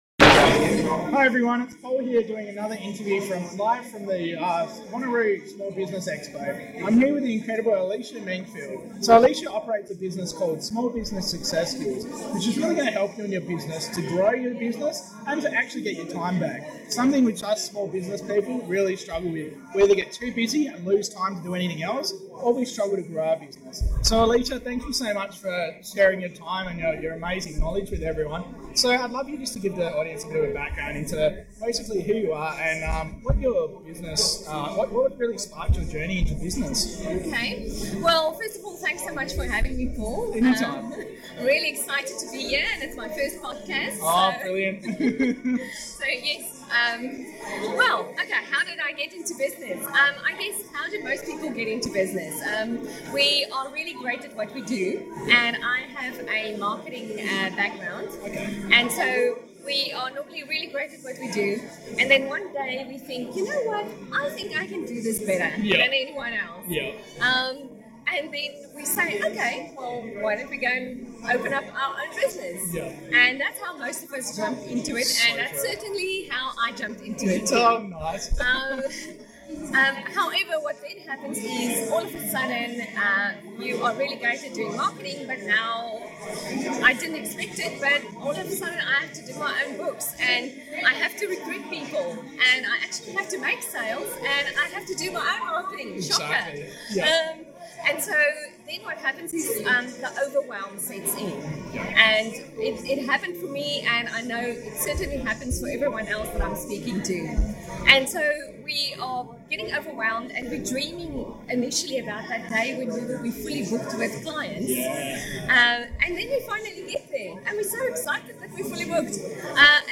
Podcast interview
from the Wanneroo Small Business Expo